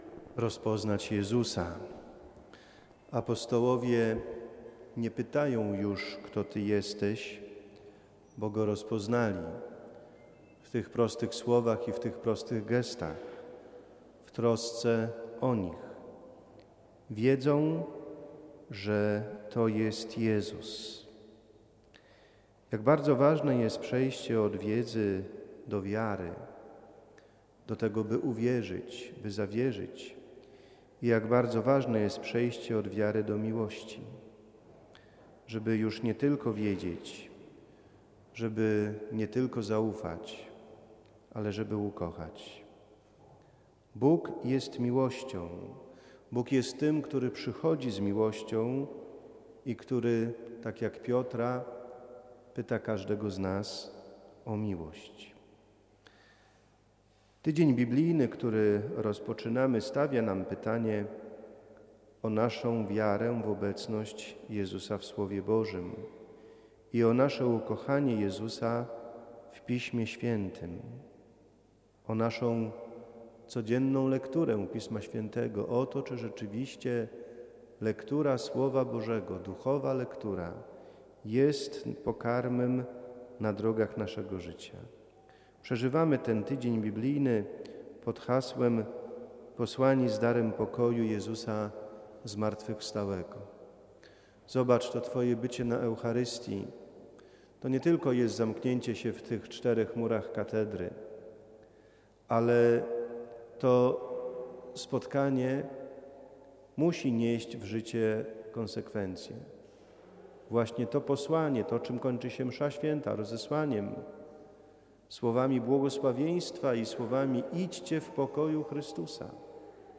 III Niedziela Wielkanocna [Homilia] - Radio Rodzina
Homilia-1-maja-2022.mp3